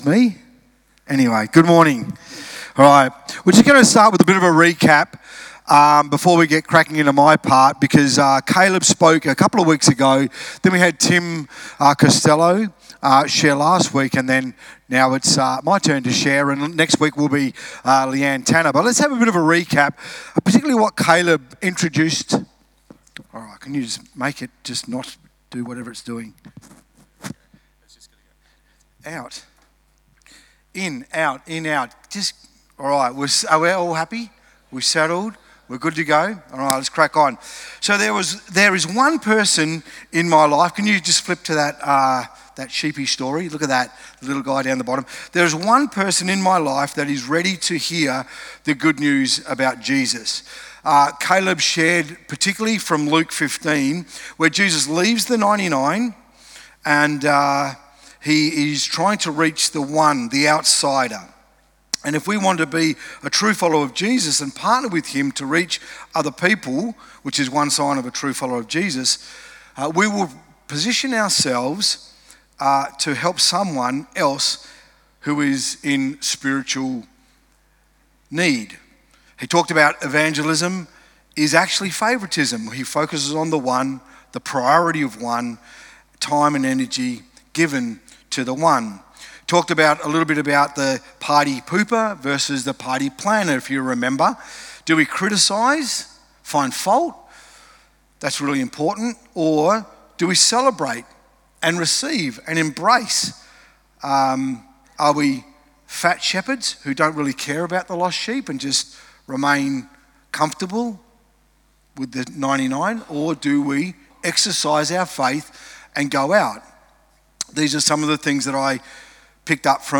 Sermons | Discover Church